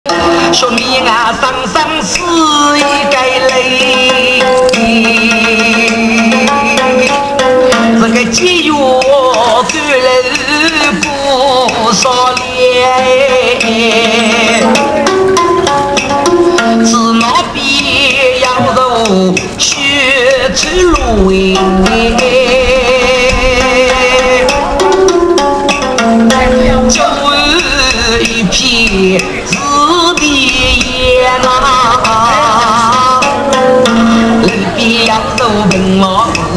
Wenzhou "Guci"
a form of ballads and story-telling